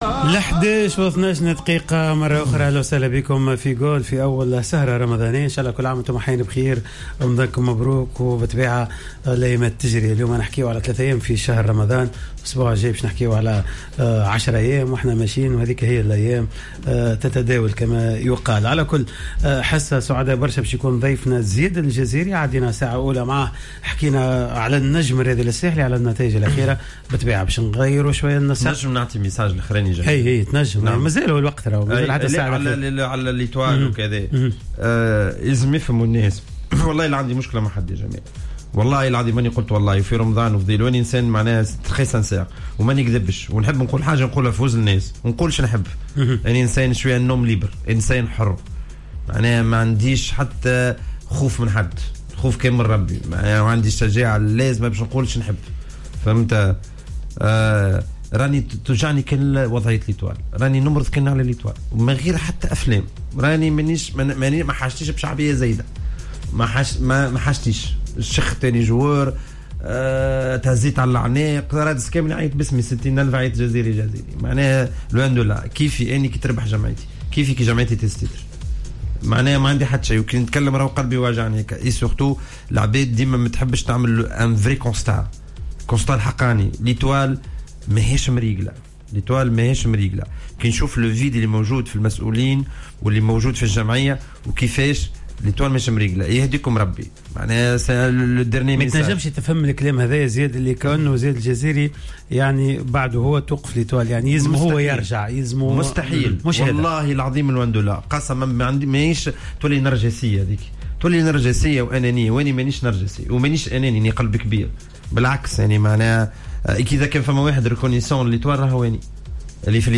استضافت حصة "قوول" ليوم الخميس 15 افريل 2021، اللاعب السابق للمنتخب الوطني التونسي و النجم الرياضي الساحلي زياد الجزيري الذي تطرق للعديد من المواضيع مثل: